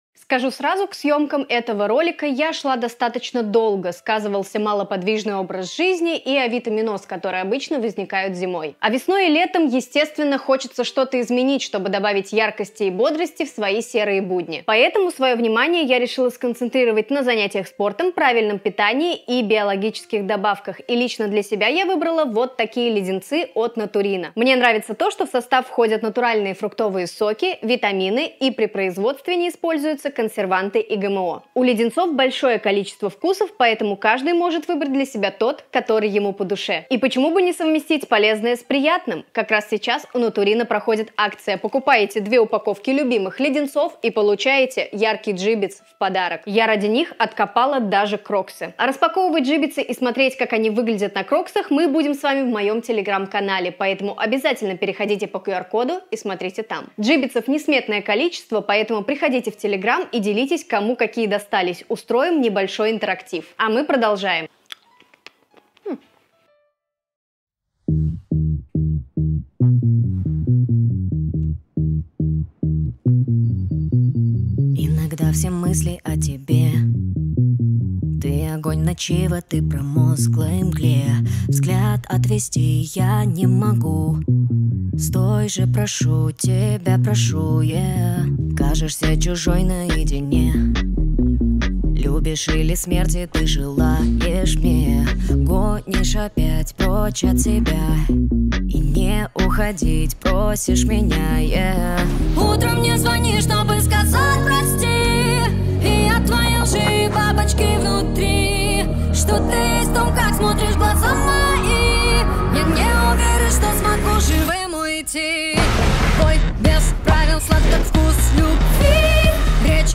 (Russian cover)